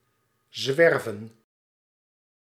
Ääntäminen
IPA: /zʋɛɾ.və:/